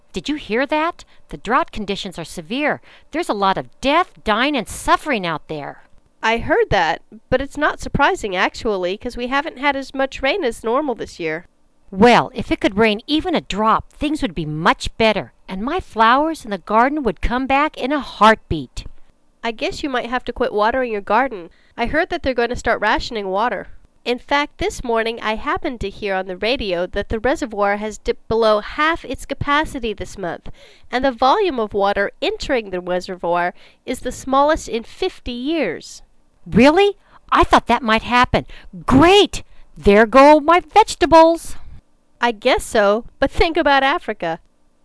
مکالمات واقعی زبان انگلیسی (آب و هوا): وضعیت خشکسالی خیلی بدجوره!